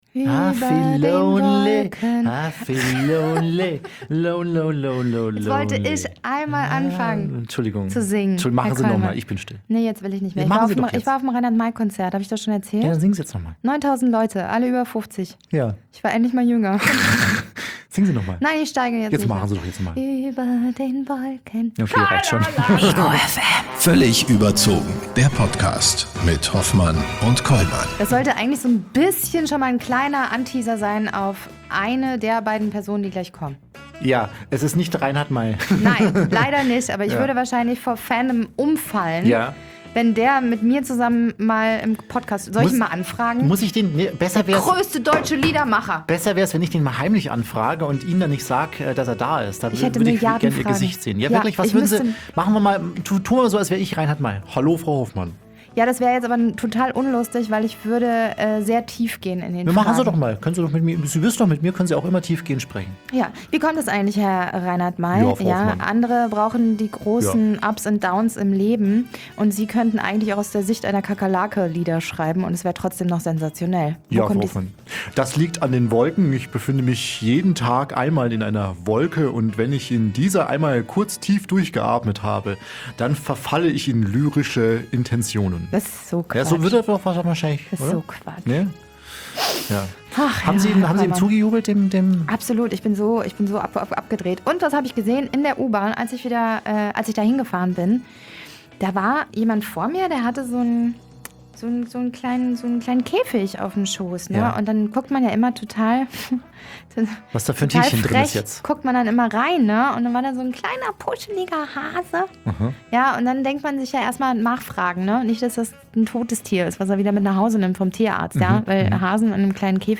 Gast: Linda Zervakis & Insa Thiele-Eich (Moderatorin & Wissenschaftlerin) In der 91ten Folge von "Völlig überzogen" begrüßen wir Linda Zervakis & Insa Thiele-Eich.